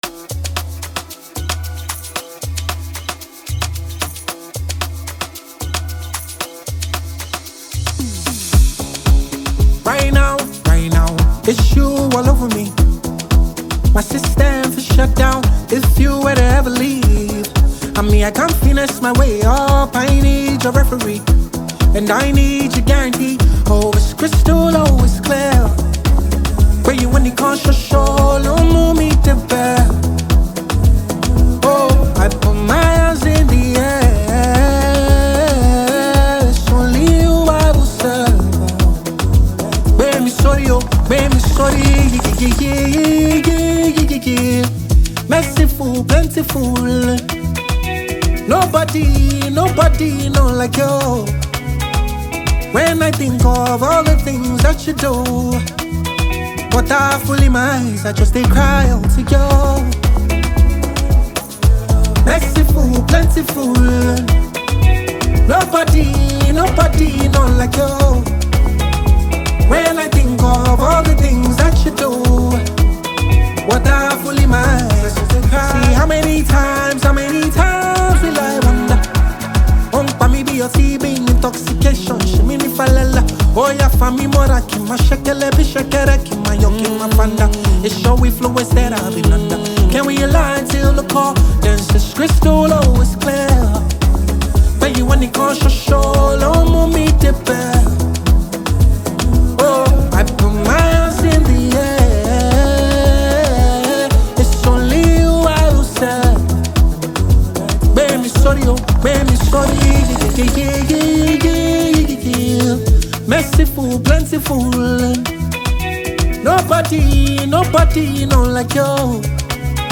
January 22, 2025 Publisher 01 Gospel 0